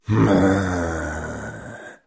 Звуки злости, ворчання
Злое мужское ворчание